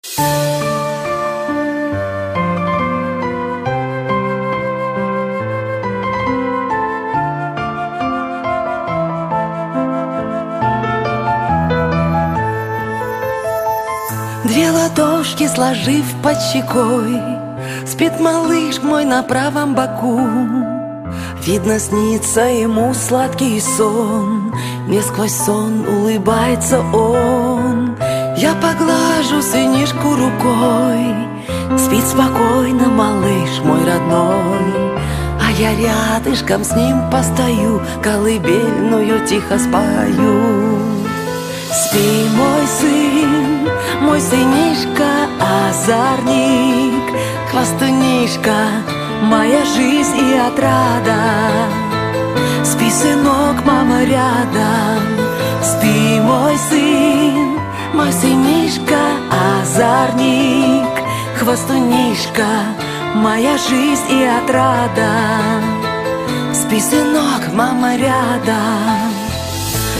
женский вокал
лирика